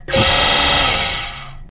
Thruster Quick, Reversed Loop